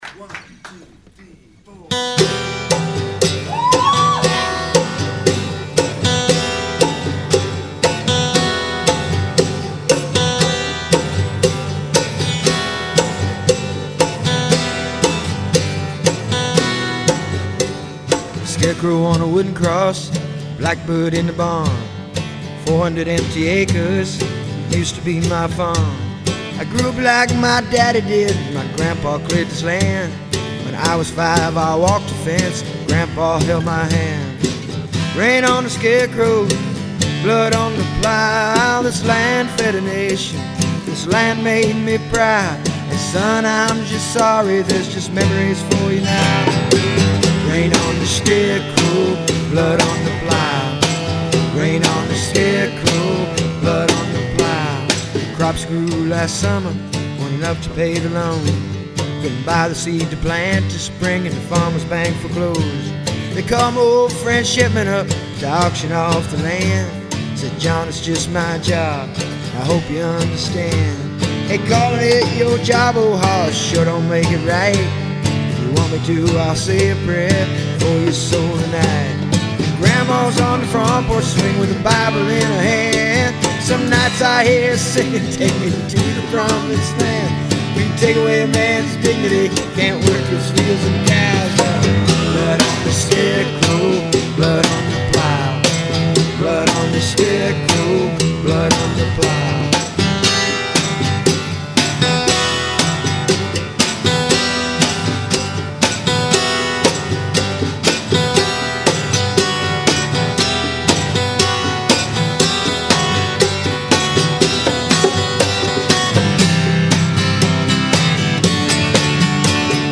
guitar
Straight up live, no overdubs.